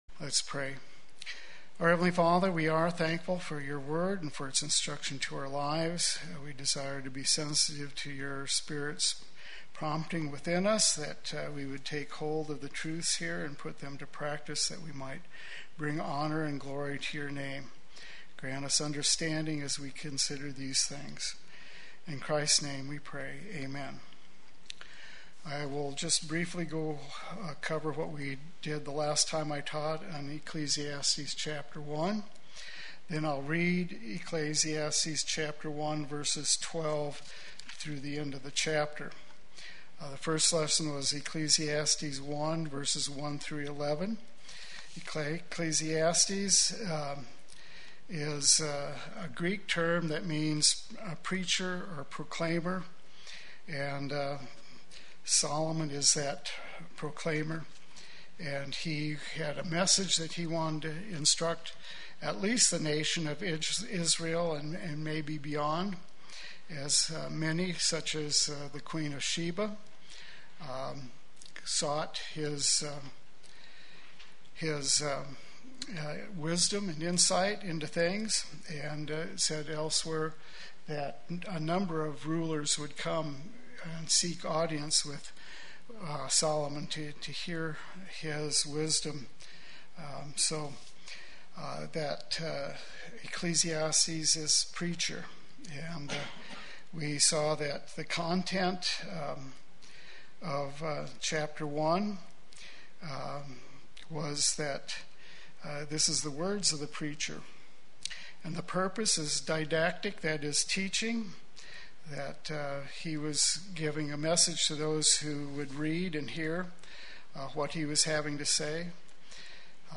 Play Sermon Get HCF Teaching Automatically.
Solomon’s Assessment of the Pursuit of Wisdom Wednesday Worship